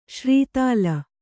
♪ śrī tāla